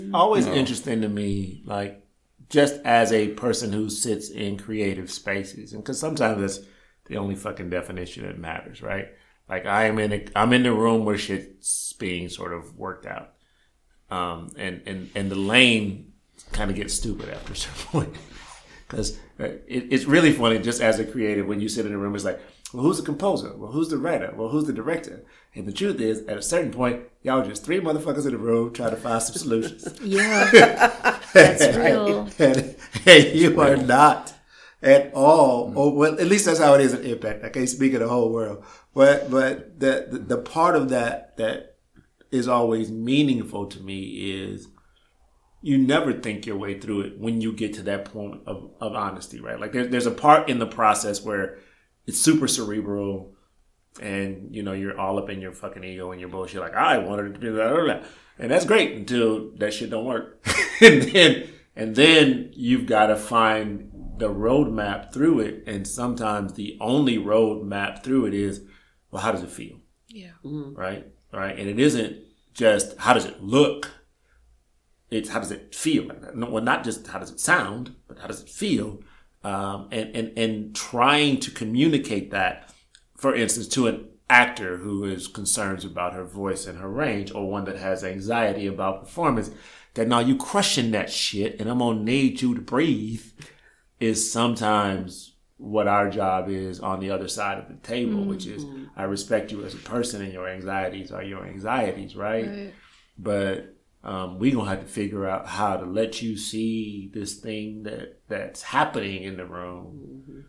ballads